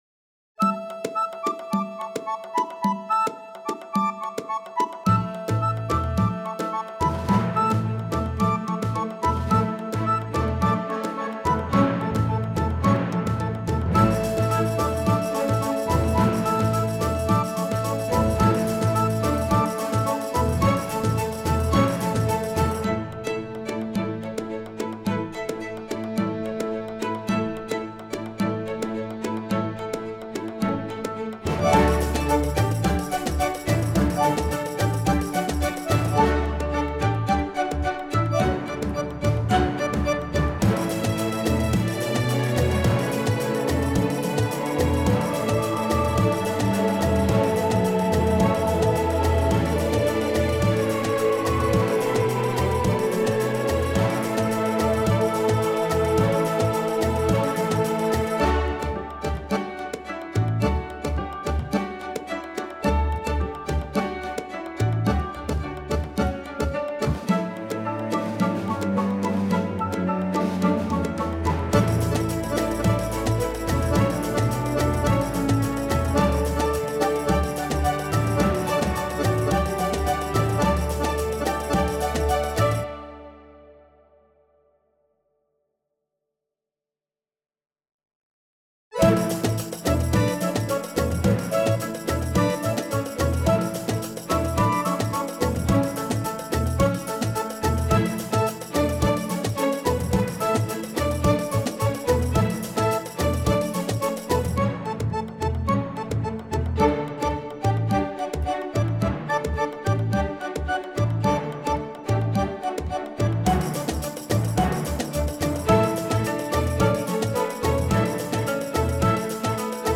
inspired by traditional partisan song